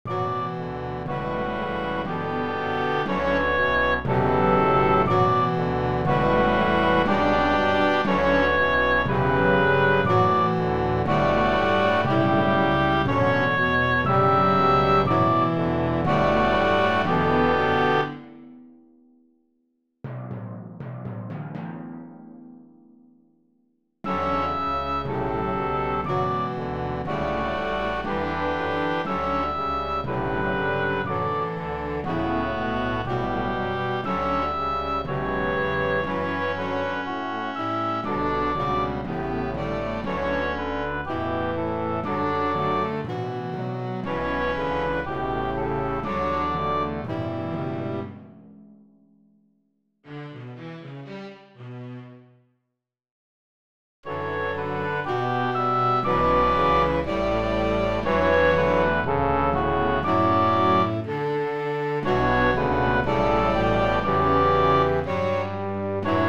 From my Third most recent original musical composition Symphony; Duisburg Somer.